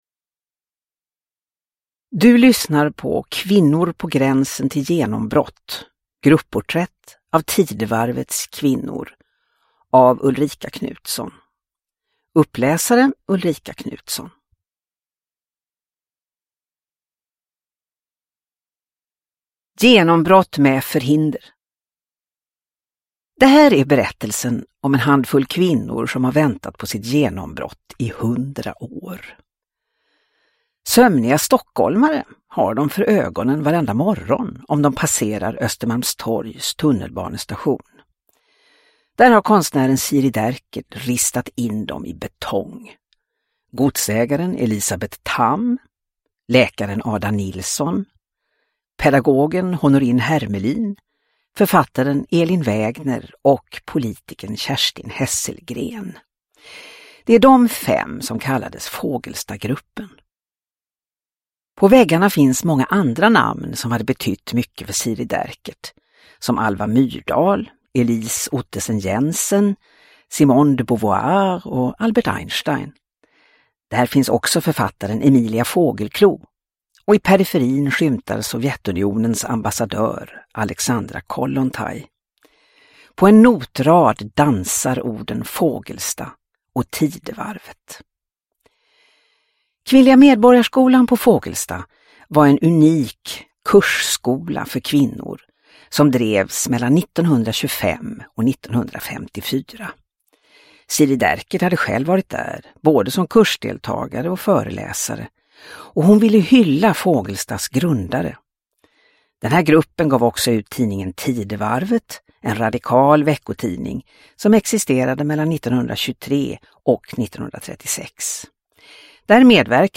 Kvinnor på gränsen till genombrott : grupporträtt av Tidevarvets kvinnor – Ljudbok – Laddas ner